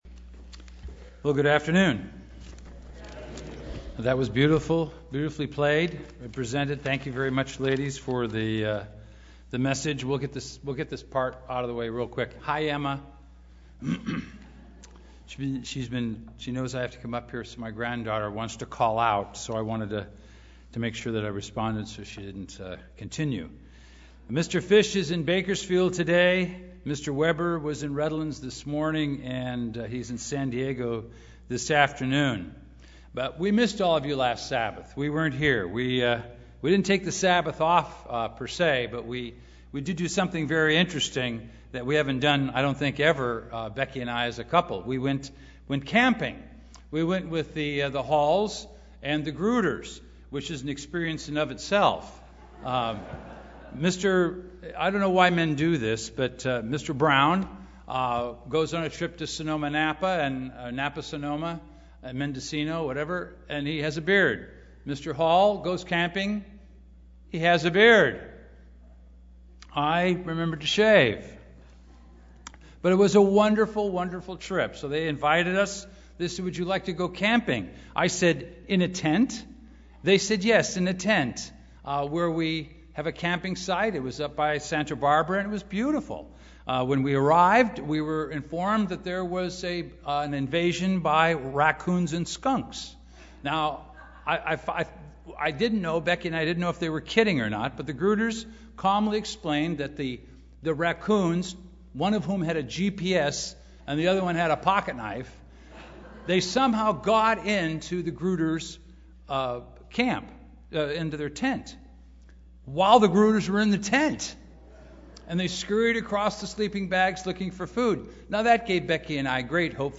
Given in Los Angeles, CA
Print Based on Romans 1, this is the first of a series of three sermons on the ideology of atheism and the lie: “There is no God” UCG Sermon Studying the bible?